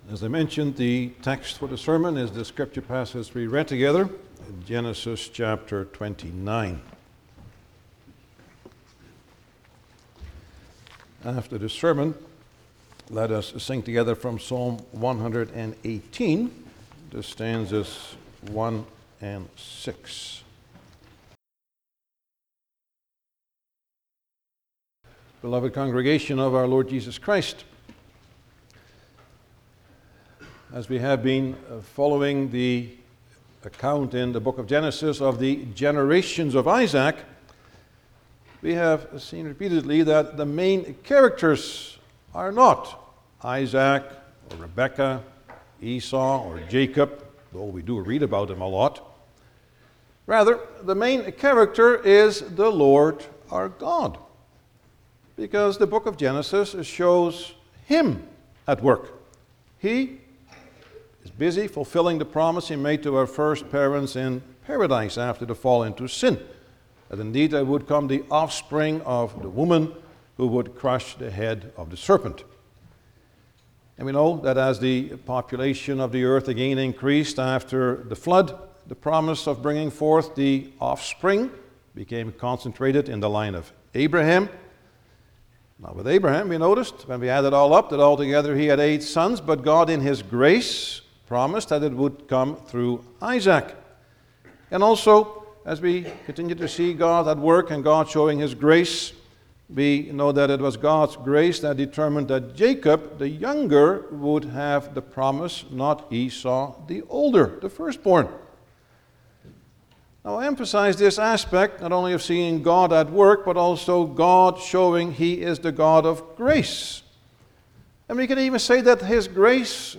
Genesis 29 Service Type: Sunday morning Bible Text